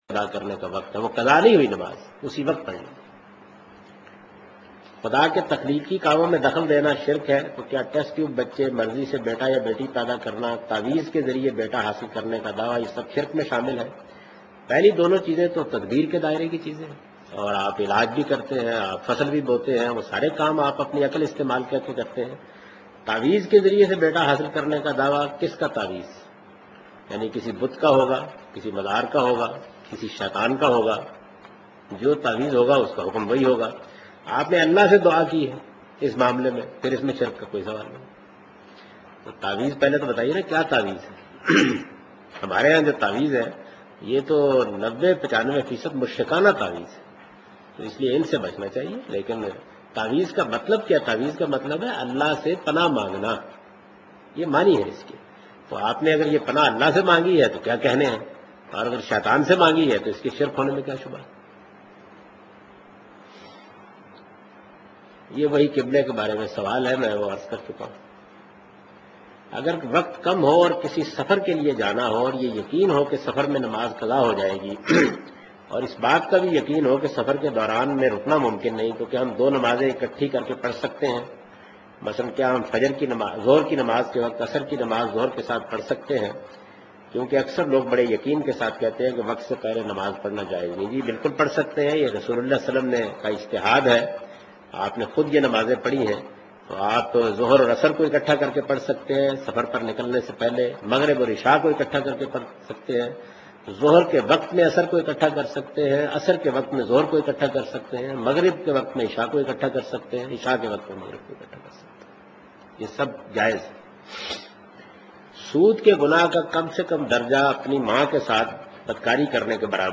اس پروگرام میں جاوید احمد غامدی عمومی سوالات کا جواب دے رہے ہیں